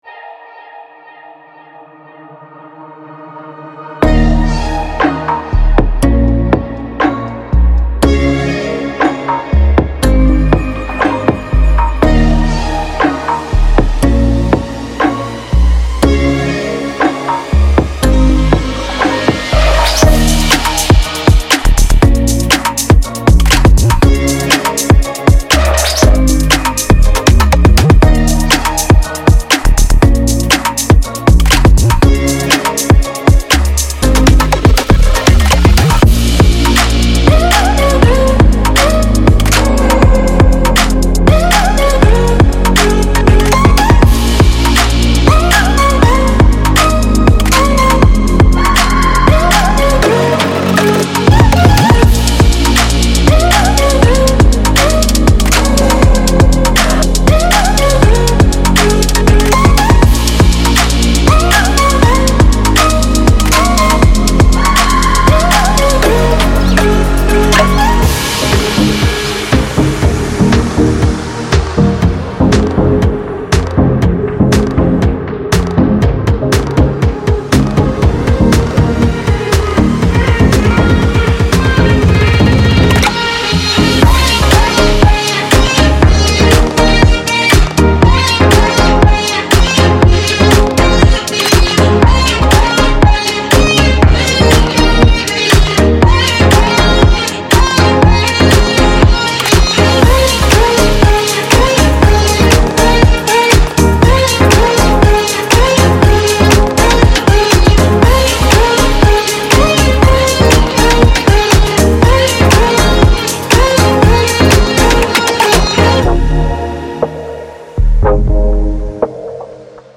整个样本包围绕和声构建，可为您提供各种谐波回路，您可以轻松地使用它们开始创作歌曲。
作为完美的伴奏，您还会发现新鲜而明亮的打击乐，旋律元素，鼓，声音纹理和丰富的FX元素集合。
•速度- 108120 BPM